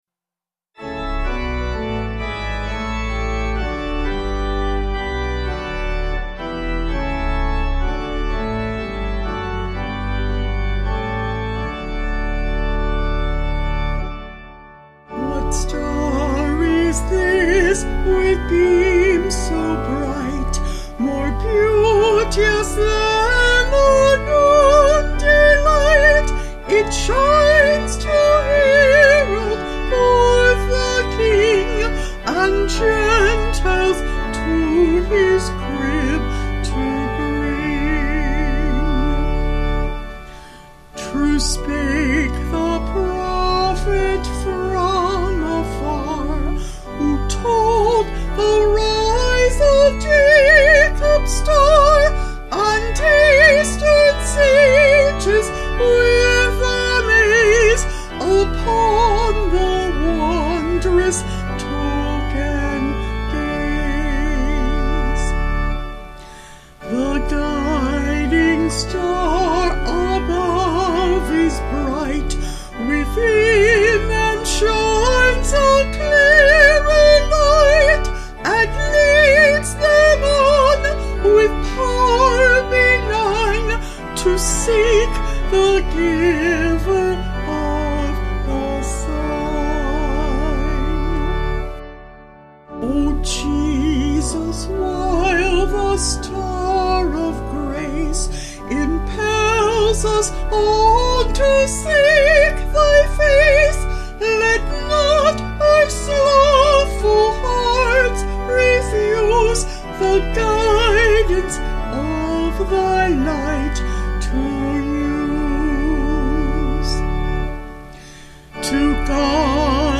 Vocals and Organ   235.4kb Sung Lyrics